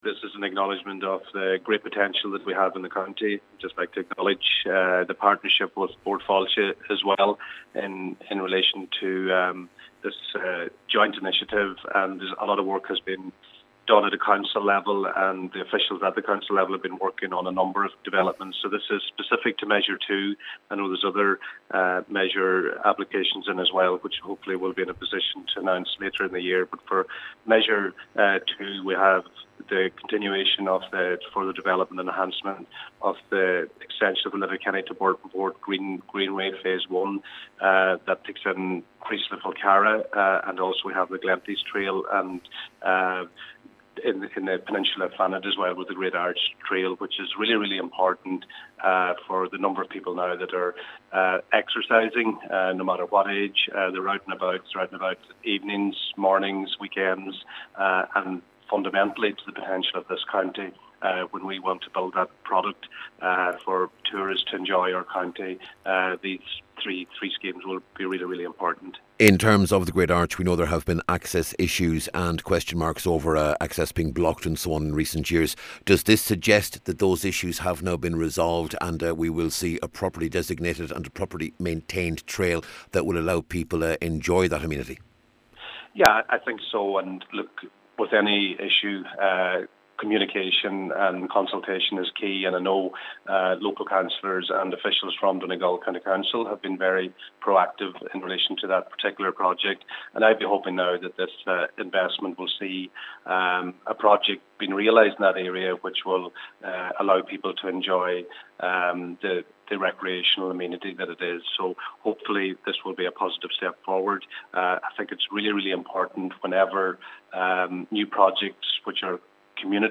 Minister Joe Mc Hugh says the funding is significant…….